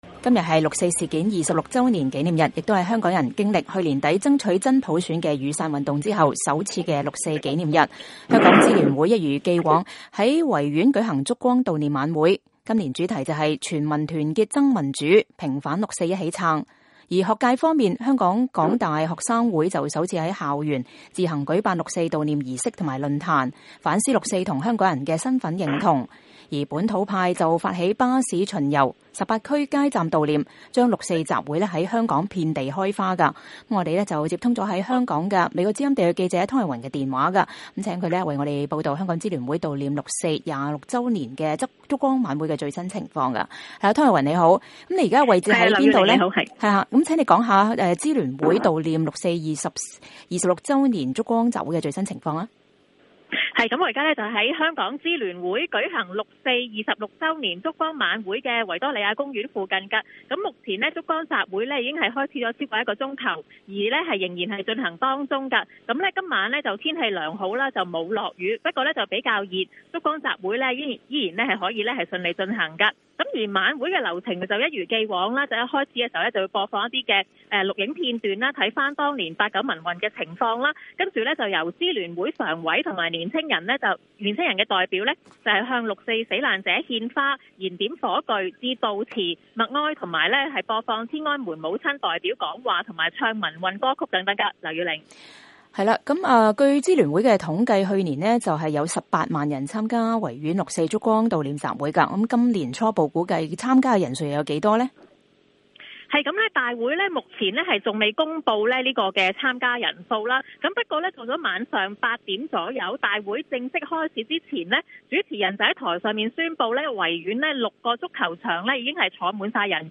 現場連線報導：香港13萬人參加維園六四燭光晚會